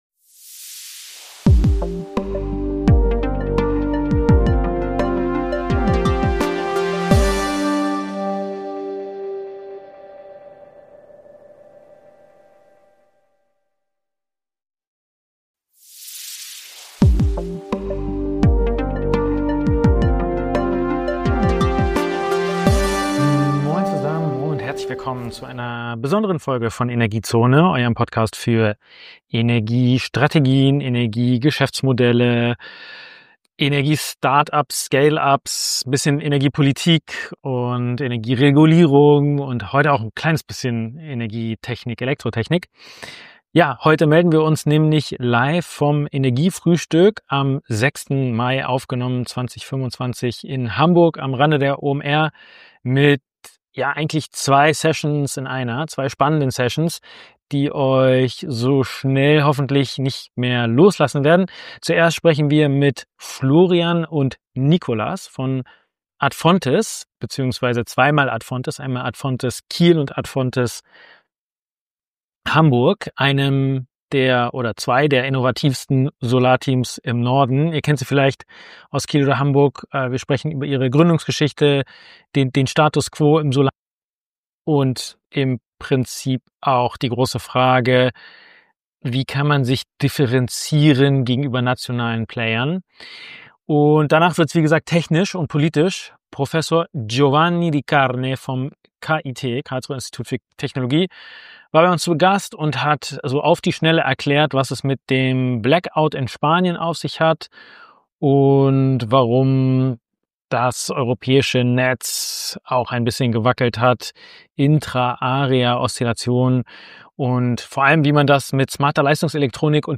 Beschreibung vor 11 Monaten In dieser Episode berichten wir live vom Energiefrühstück in Hamburg, einem spannenden Event am 6. Mai 2025, das sich mit den neuesten Entwicklungen in der Energiewirtschaft beschäftigt. Wir bieten euch zwei fesselnde Sessions in einer Folge, die sich mit innovativen Energietechnologien und den Herausforderungen der Energiewende auseinandersetzen.